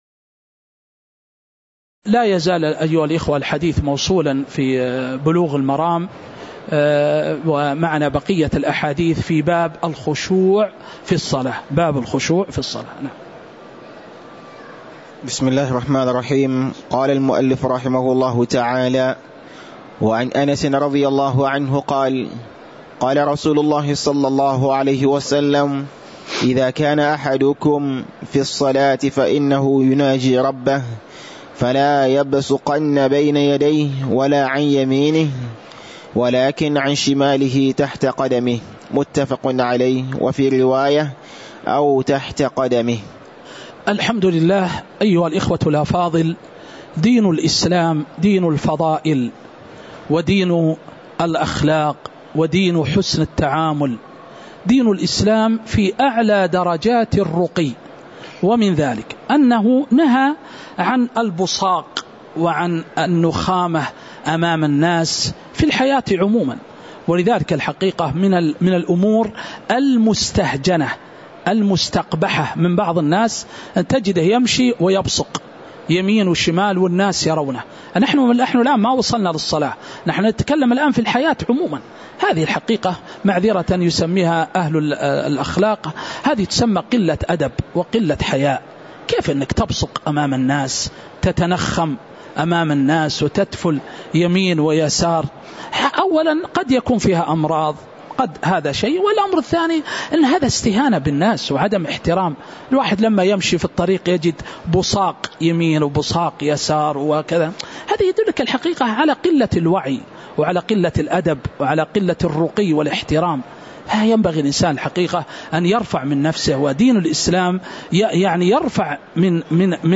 تاريخ النشر ٢ ربيع الأول ١٤٤٥ هـ المكان: المسجد النبوي الشيخ